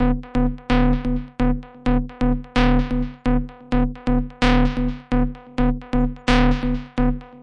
描述：Tech Loop 125 bpm
标签： 最小 电子 125BPM 环路 电子乐 配音
声道立体声